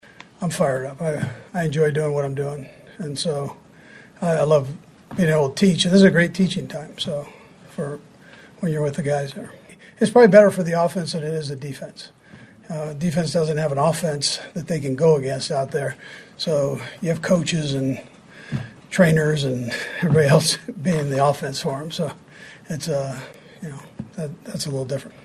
Coach Andy Reid says he is ready to go.
5-25-andy-reid.mp3